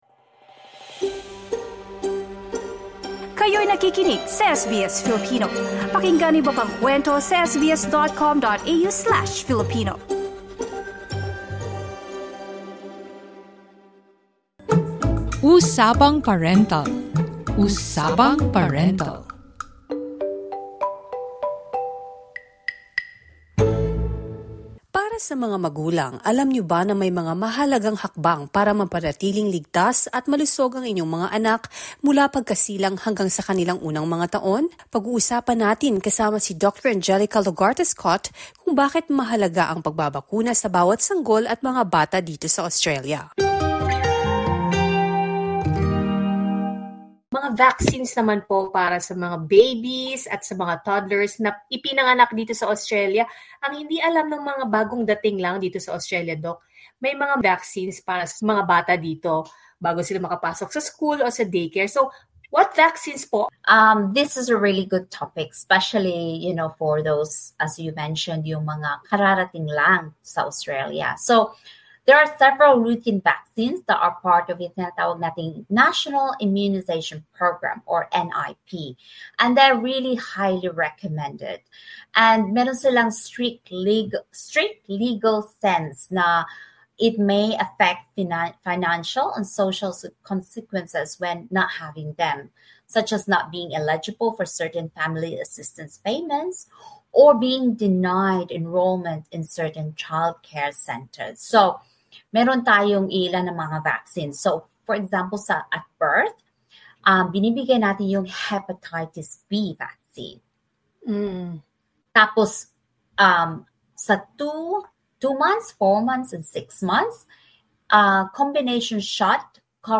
Here's why immunisation is essential SBS Filipino 10:44 Filipino 'Usapang Parental' is SBS Filipino's segment on parenting.